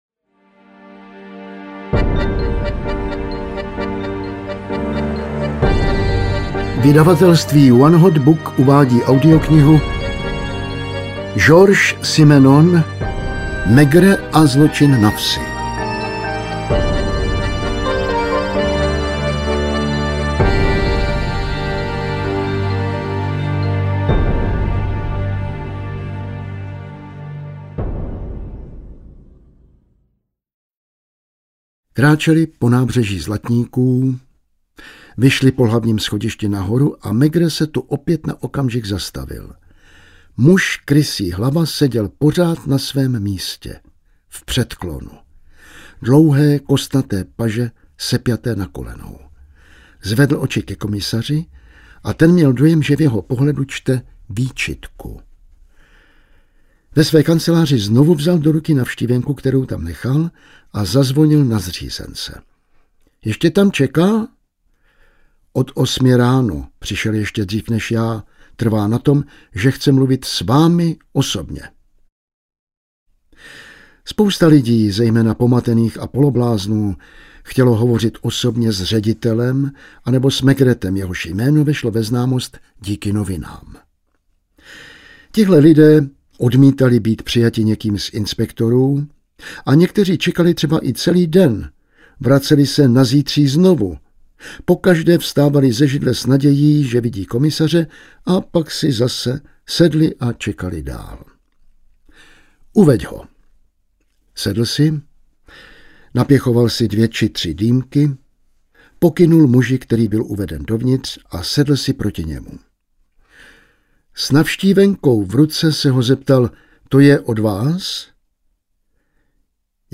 Maigret a zločin na vsi audiokniha
Ukázka z knihy